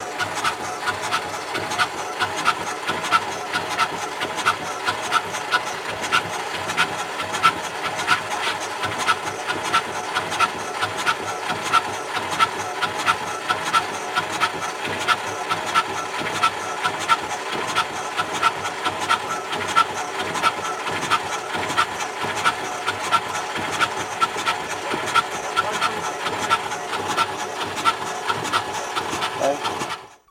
Airplane Windshield Wipers On Airplane In Snow